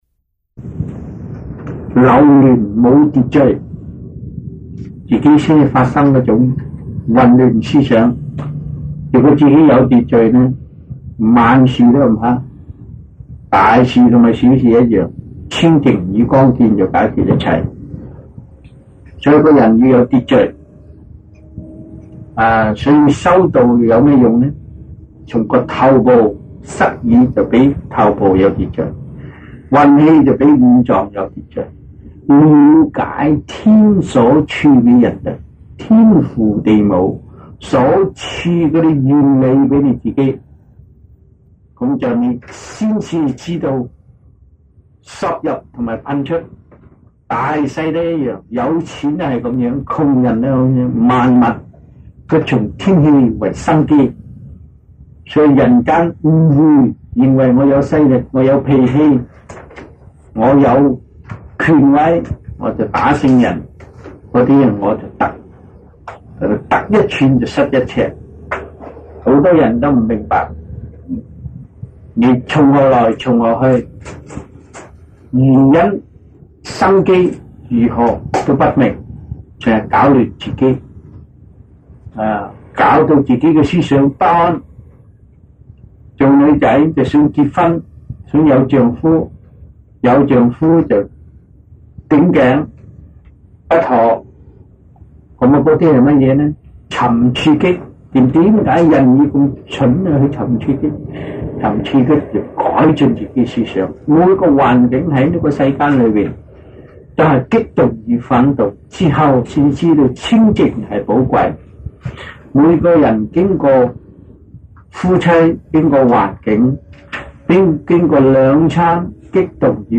Q&A in Chinese-1982 (中文問答題)